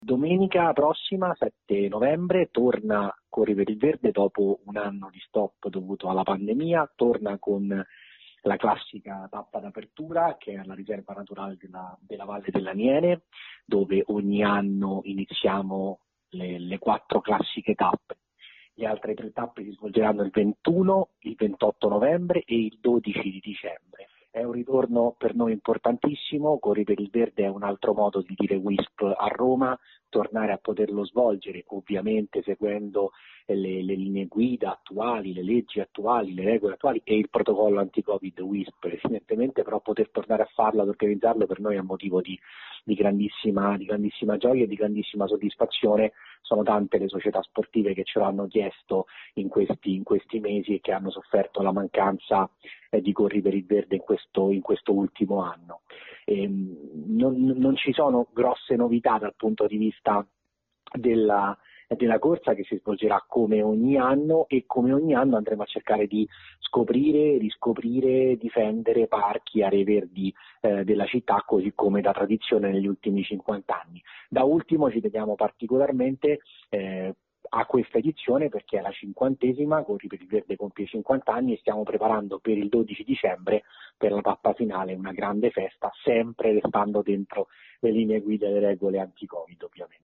In Emilia Romagna al via la prima sperimentazione che propone un’ora di educazione fisica per tutti i bambini delle elementari. Il servizio